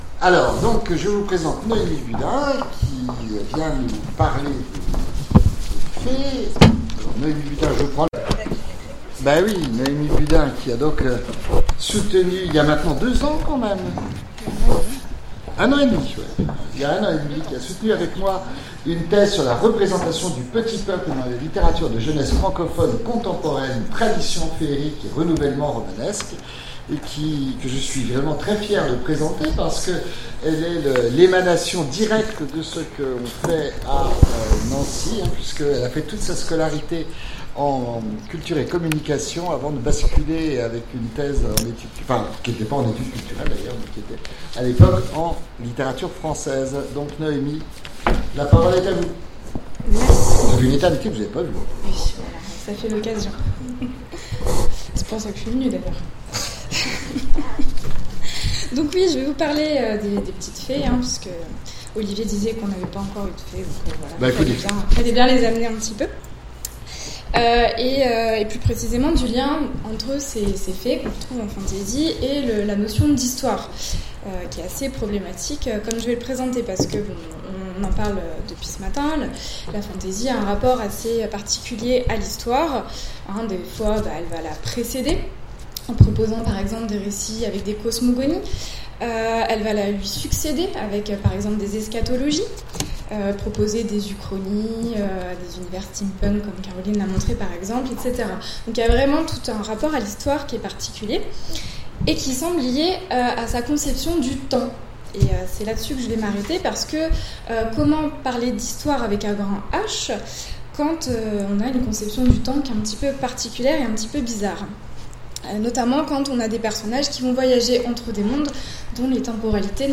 Colloque universitaire 2018 : Les Fées historiques, entre Histoire et fiction
Conférence